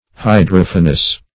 Search Result for " hydrophanous" : The Collaborative International Dictionary of English v.0.48: Hydrophanous \Hy*droph"a*nous\, a. (Min.)